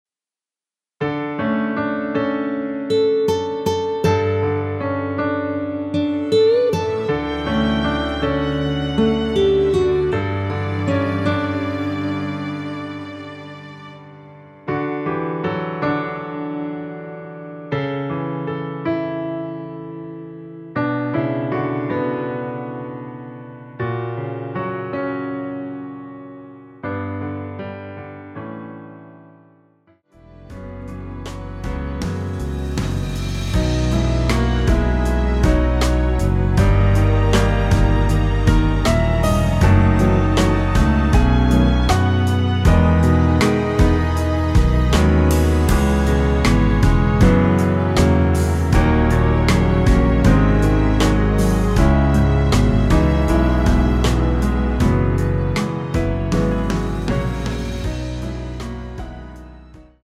원키에서(-6)내린 MR입니다.
앞부분30초, 뒷부분30초씩 편집해서 올려 드리고 있습니다.
중간에 음이 끈어지고 다시 나오는 이유는